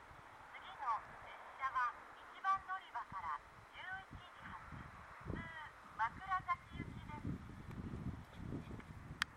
この駅では接近放送・予告放送が設置されています。
予告放送普通　枕崎行き予告放送です。